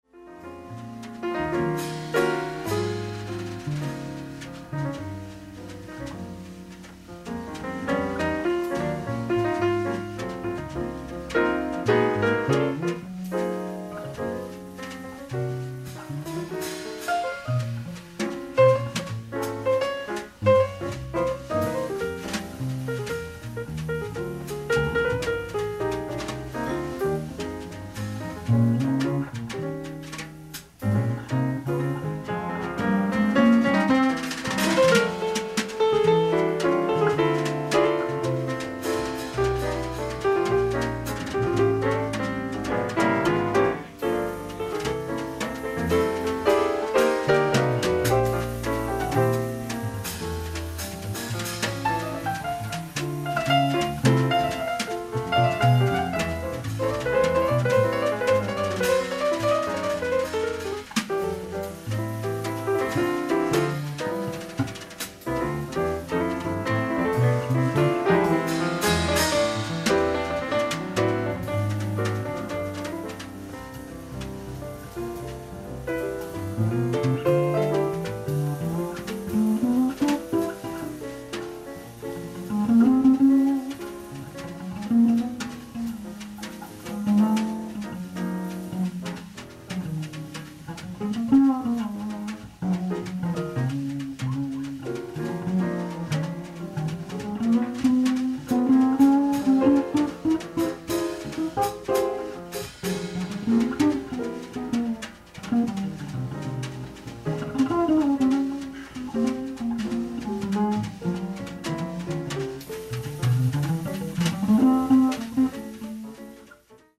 ディスク１：ライブ・アット・ E.J,'s、アトランタ、ジョージア 08/22/1981
※試聴用に実際より音質を落としています。